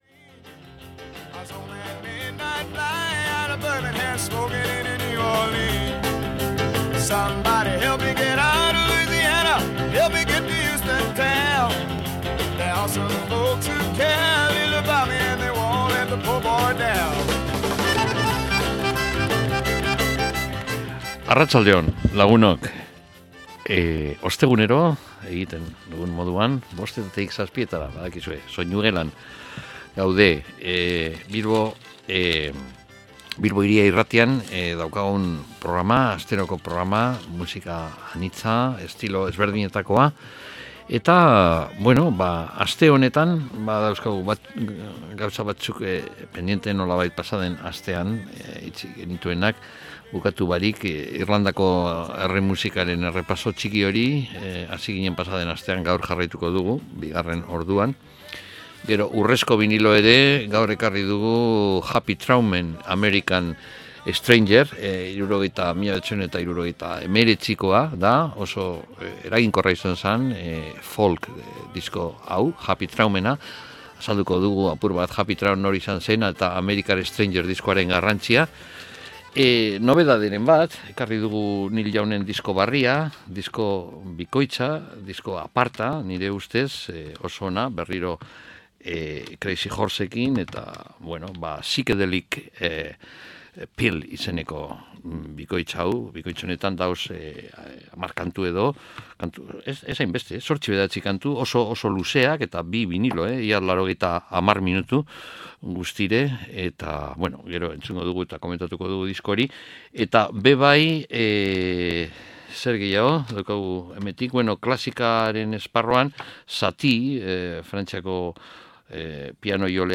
Irlandako musikak sortuak